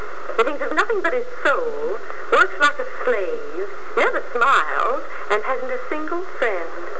Click on the Nan button to hear to a rare soundbite of Nancy delivering a line from the condemned movie " Springtime for Henry "